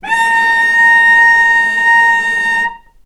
vc-A#5-ff.AIF